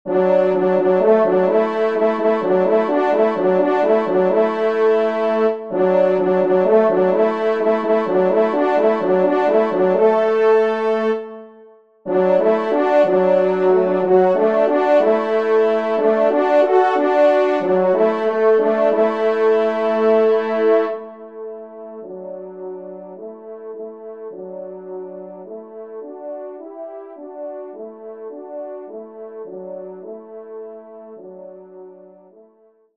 Genre :  Divertissement pour Trompes ou Cors
2ème Trompe